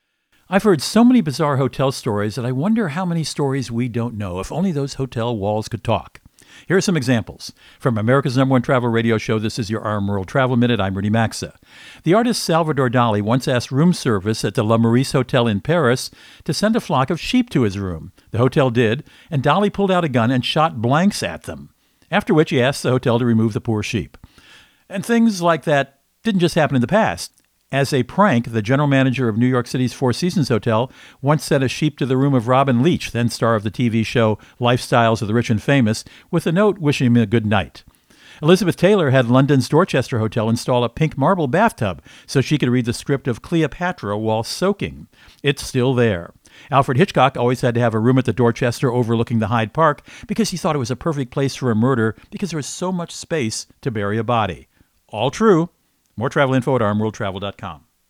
Co-Host Rudy Maxa | Talking Hotel Walls (if only) …